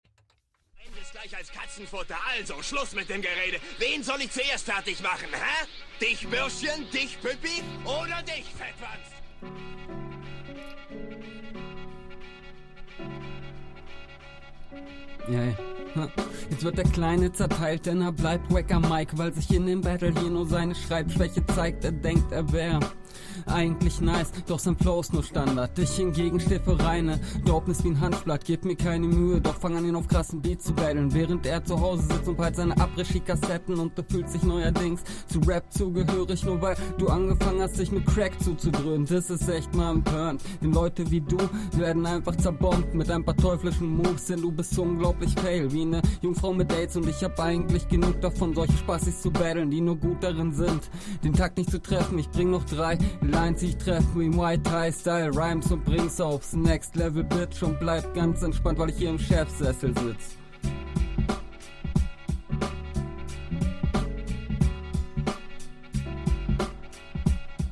Aber irgendwie ist der Mix selstsam find ich. …